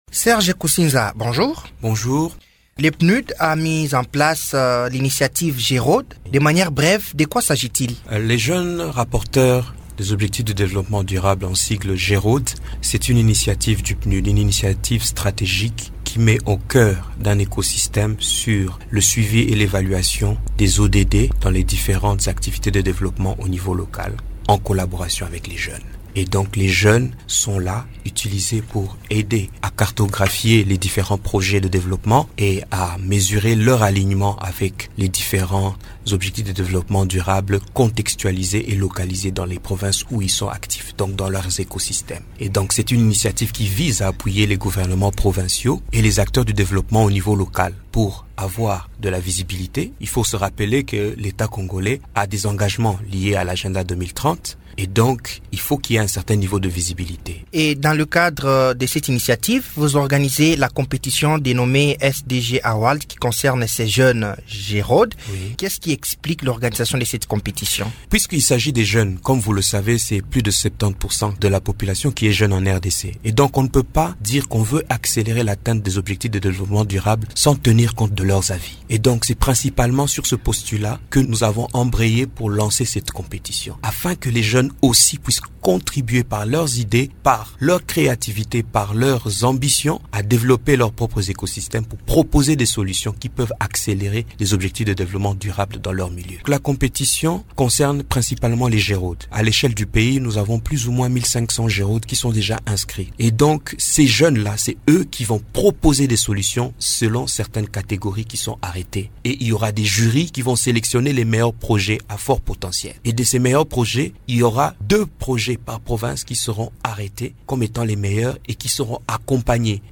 Politique, Actualité, L'invité du jour, Émissions / Représentation, Opposition, Majorité